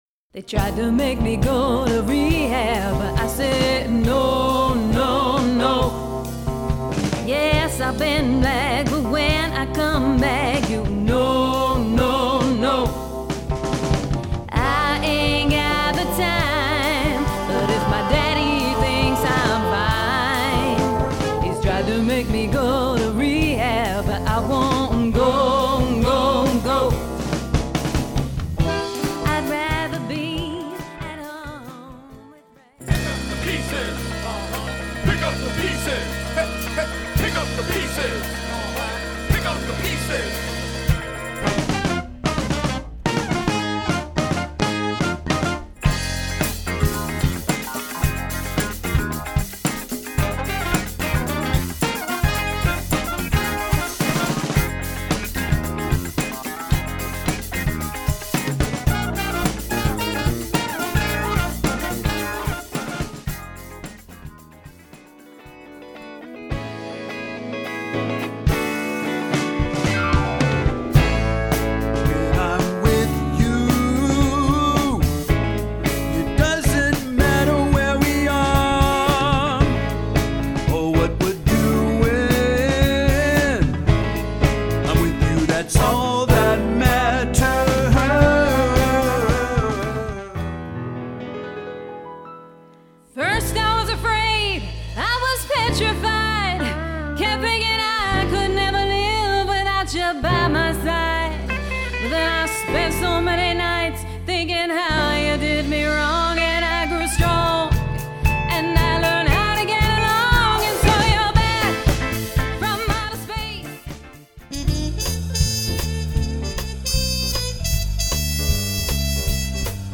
Sample Medley